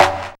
44_11_tom.wav